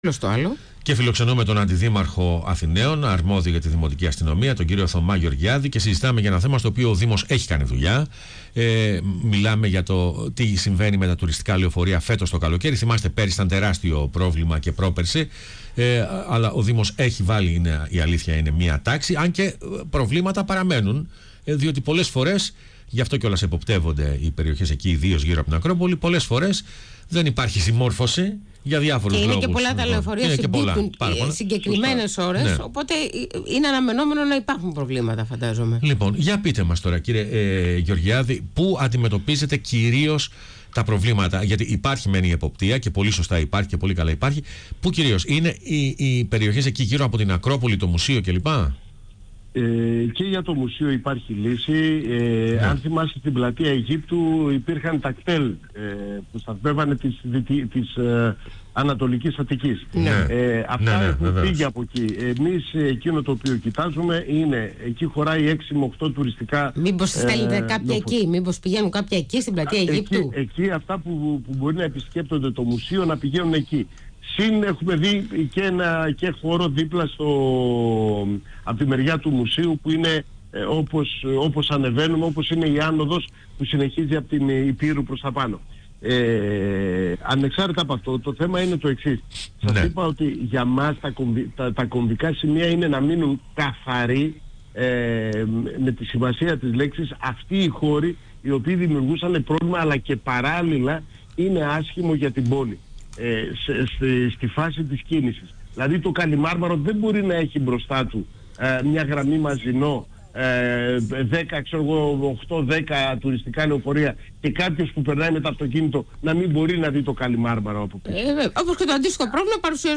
Ο Αντιδήμαρχος Δημοτικής Αστυνομίας του Δήμου Αθηναίων Θωμάς Γεωργιάδης μίλησε για το τεράστιο θέμα των τουριστικών λεωφορείων στο Κέντρο της Αθήνας στον ραδιοφωνικό σταθμό πρώτο πρόγραμμα